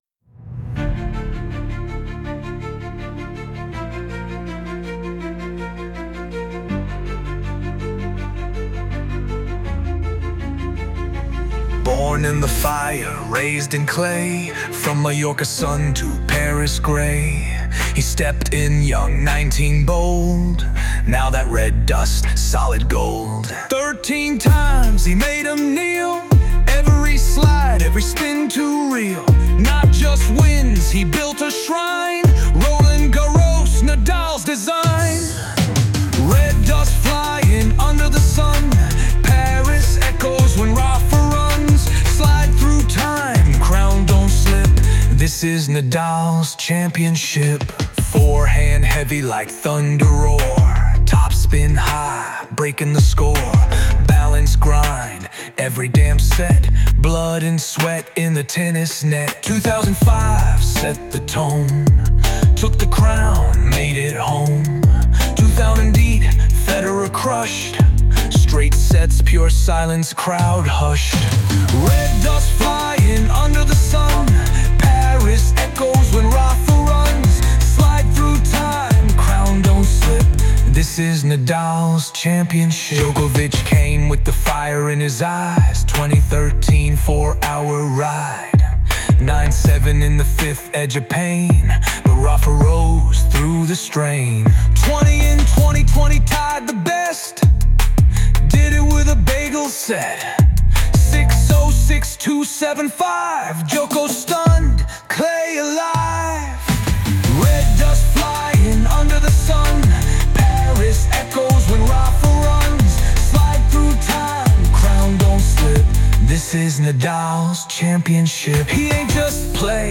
original rap tribute